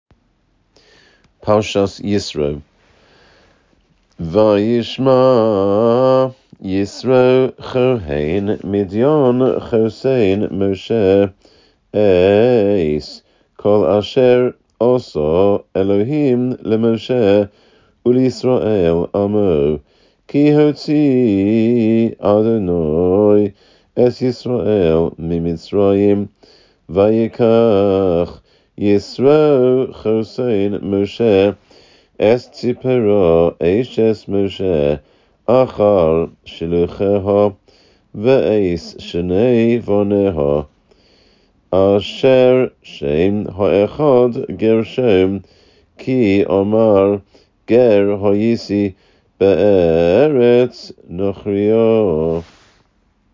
A few pesukim from the beginning of each parashah, in Ashkenazi pronunciation. Pupils can learn from these in order to prepare for their turn to lein in the Shabbos Assembly.